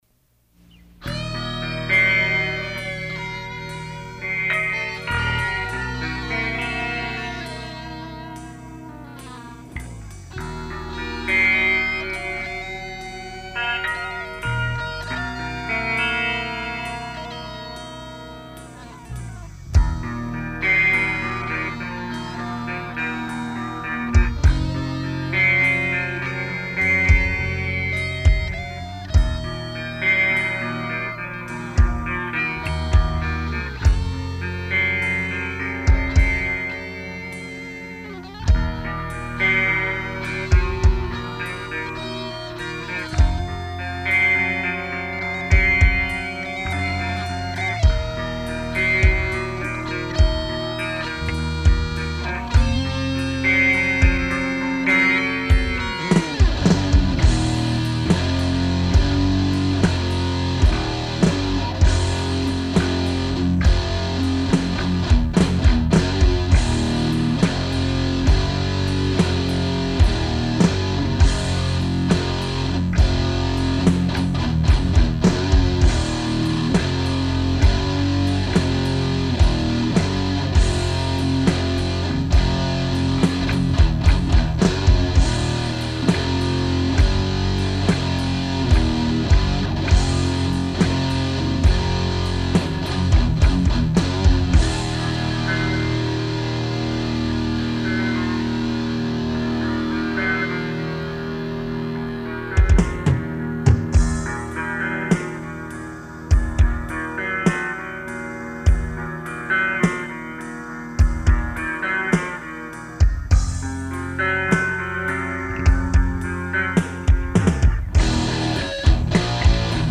I'm playing guitar/bass/drums into a Tascam 8-track: